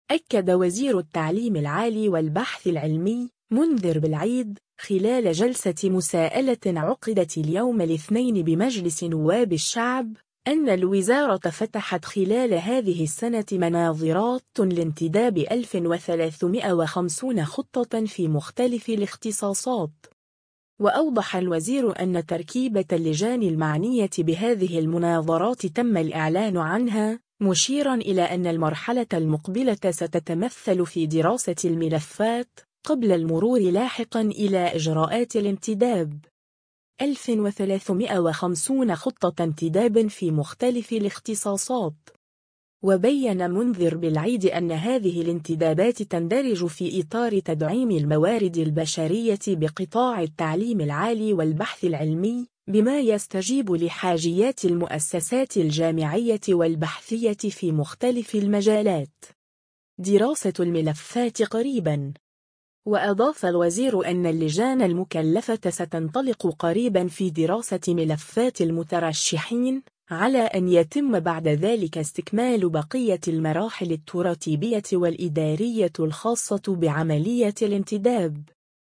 أكد وزير التعليم العالي والبحث العلمي، منذر بلعيد، خلال جلسة مساءلة عقدت اليوم الاثنين بمجلس نواب الشعب، أن الوزارة فتحت خلال هذه السنة مناظرات لانتداب 1350 خطة في مختلف الاختصاصات.